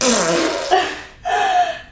Milk Fart Efecto de Sonido Descargar
Milk Fart Botón de Sonido